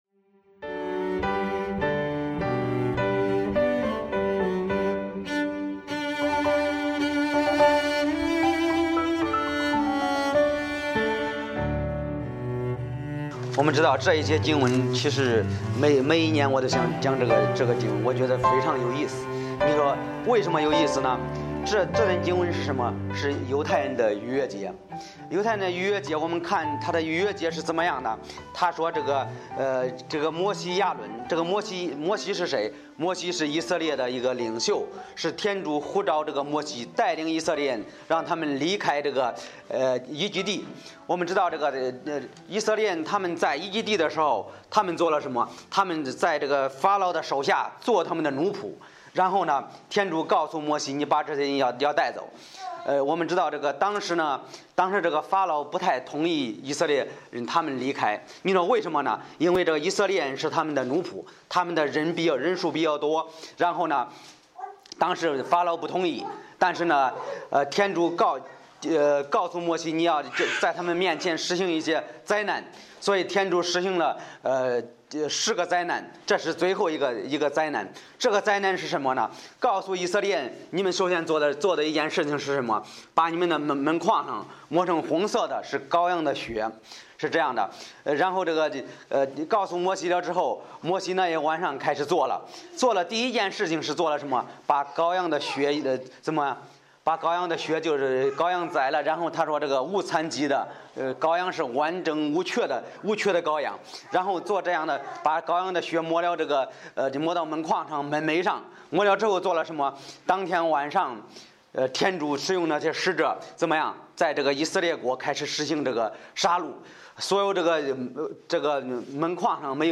Series: 周日礼拜